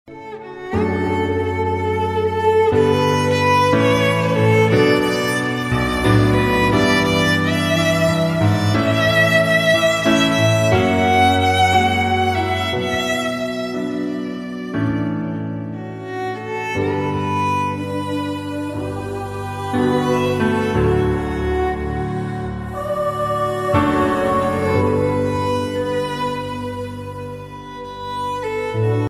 красивые
спокойные
без слов
скрипка
Violin
Инструментальная версия со скрипкой вместо вокала